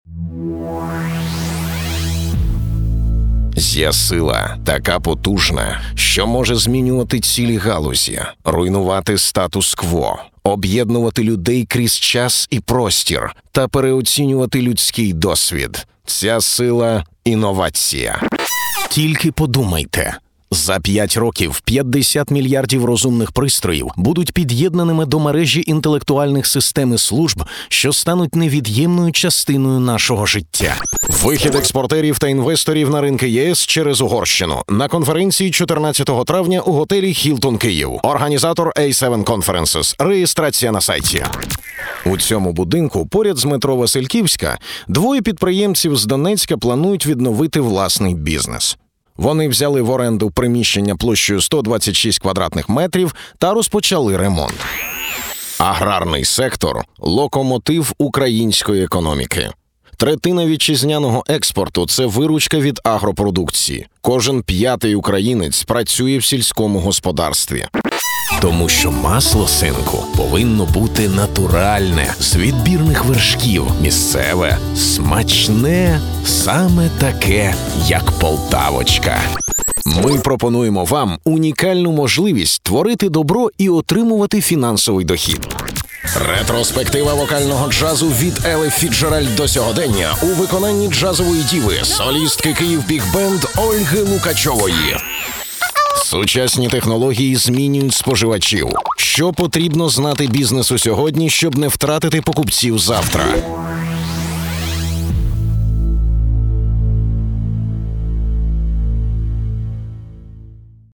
Профессиональный диктор.
Тембр моего голоса - баритон.
Тракт: mics - Rode K2, AKG Perception 220preamp - DBX 376 Tube Channel Stripinterface - Yamaha MW 10cmonitors - Yamaha HS 50mДикторская кабина.